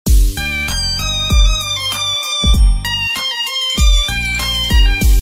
Cute Kitten Meow Songs Game Sound Effects Free Download